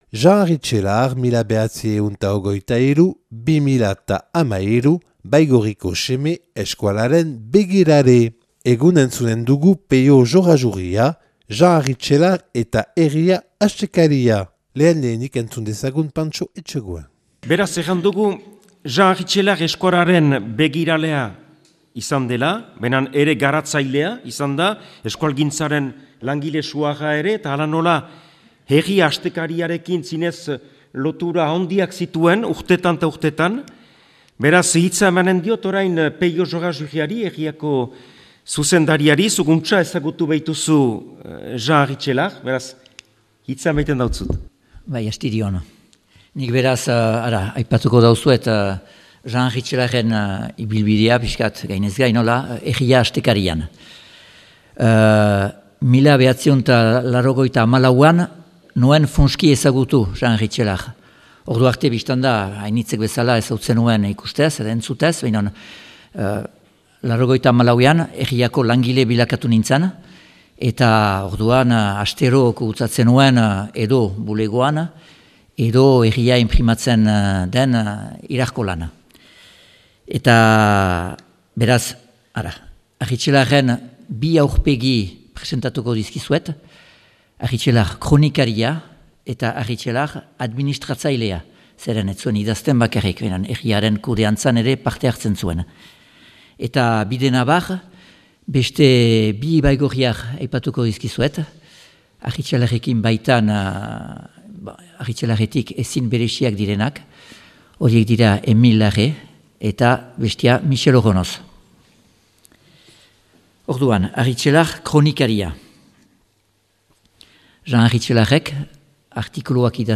Baigorrin grabatua 2023.